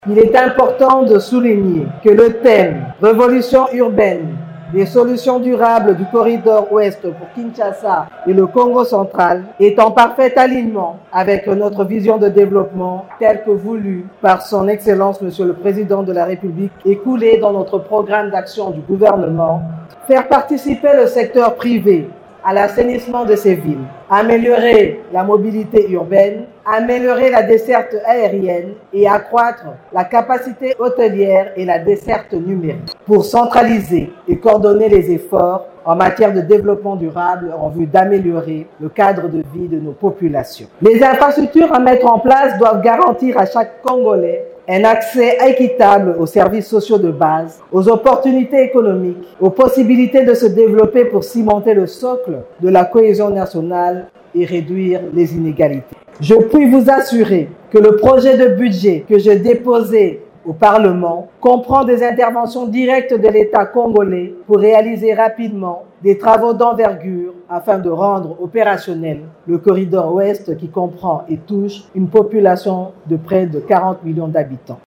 La Première ministre Judith Suminwa a lancé ce mercredi 18 septembre à Matadi, au Kongo-central, la deuxième étape des assises de la huitième édition de l'Expo Béton.
mot_premiere_ministre_expo_beton.mp3